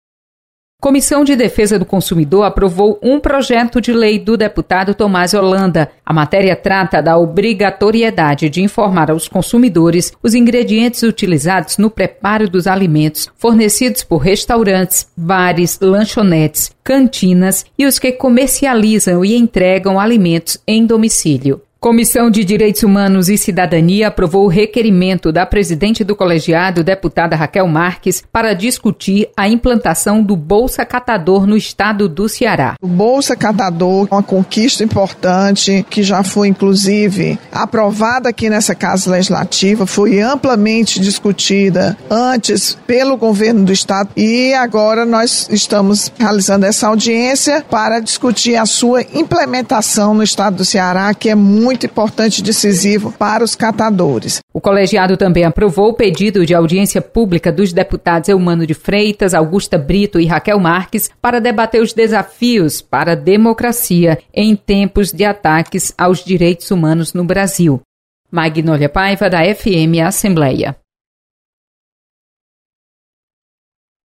Você está aqui: Início Comunicação Rádio FM Assembleia Notícias Comissões